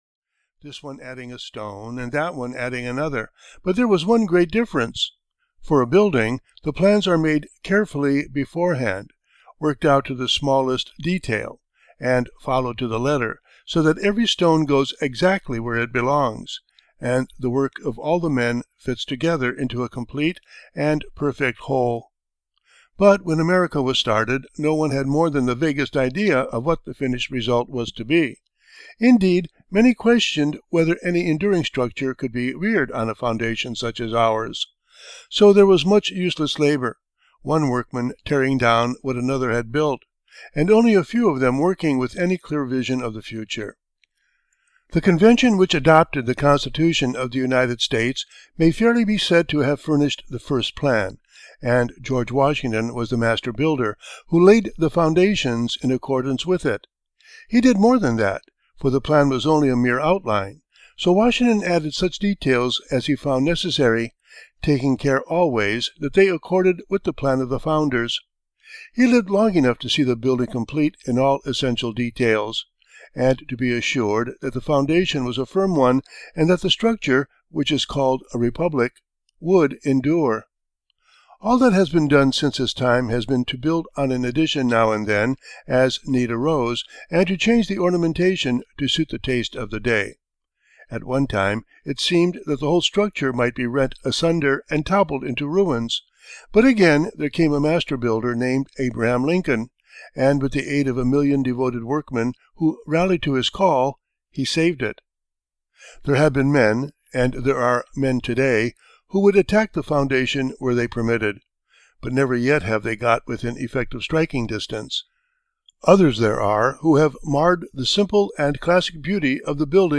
American Men of Mind (EN) audiokniha
Ukázka z knihy